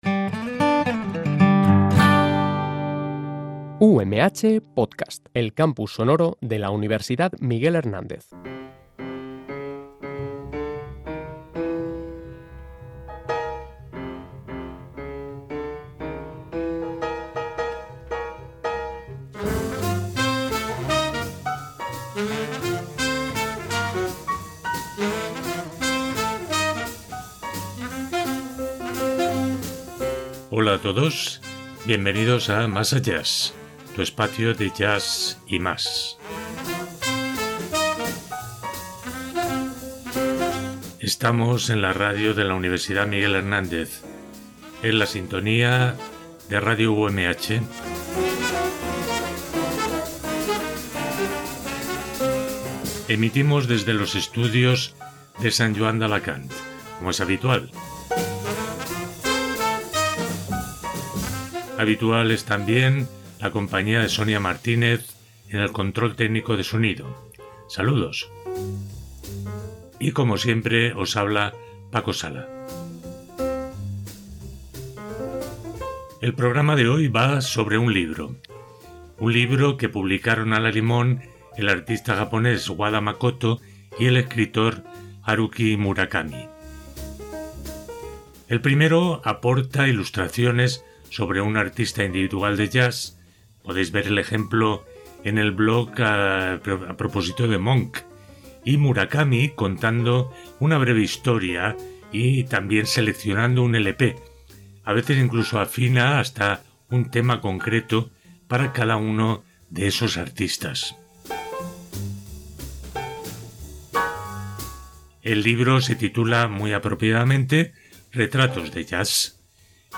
En el programa de hoy, seleccionamos solo unos pocos de los 55 retratos de Murakami, glosando sus palabras y decorándolas musicalmente con las piezas que el propio autor ha ido eligiendo para cada artista.